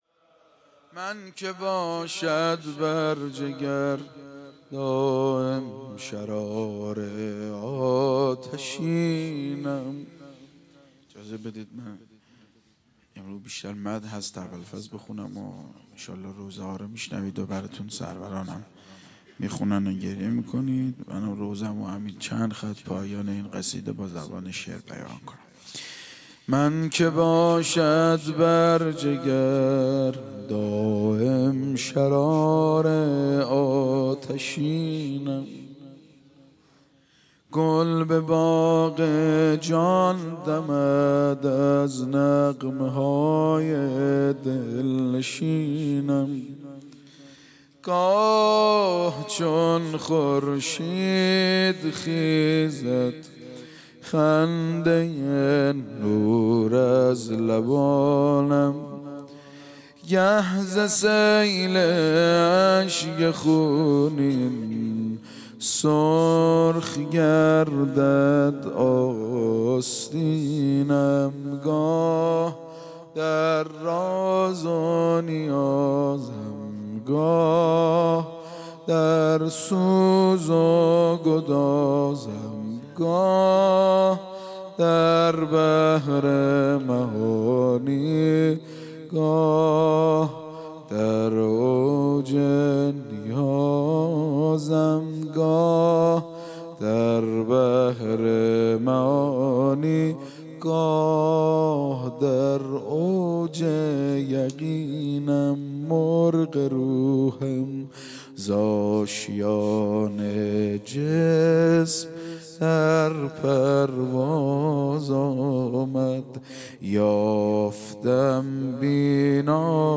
مناسبت : دهه دوم صفر
قالب : روضه